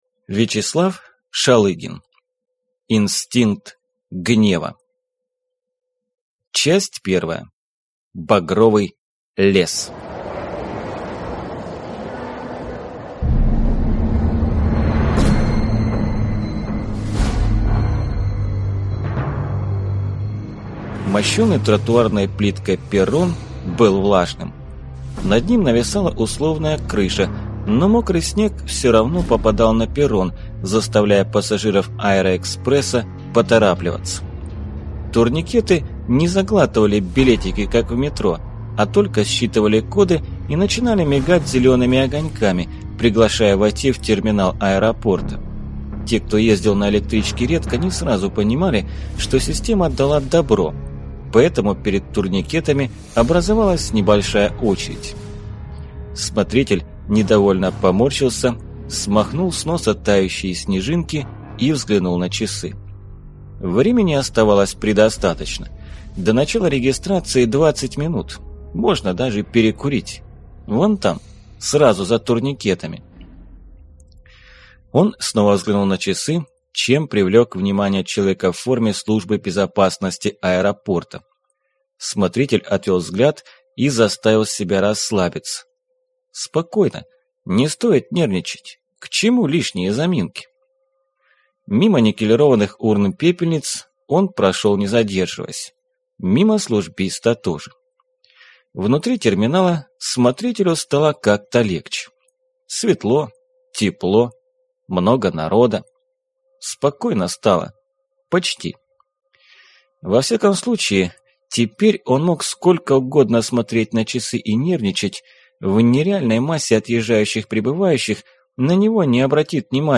Аудиокнига Инстинкт гнева | Библиотека аудиокниг